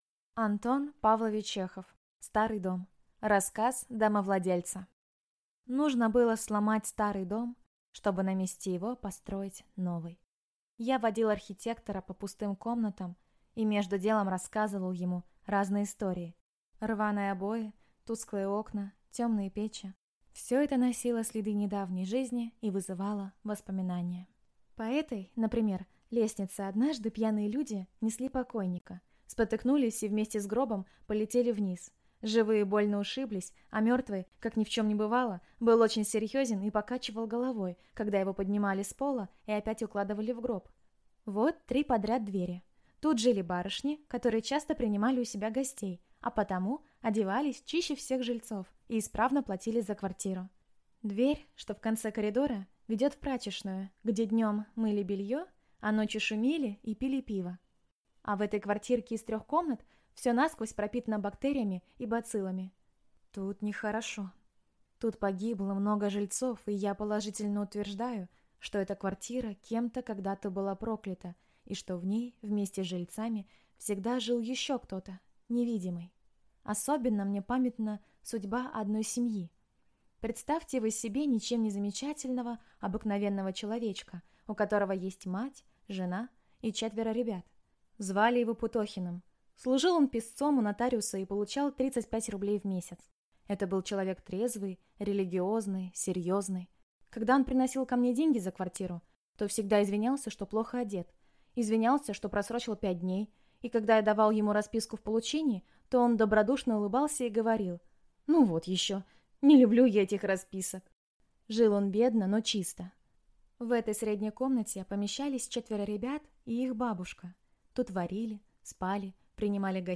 Аудиокнига Старый дом | Библиотека аудиокниг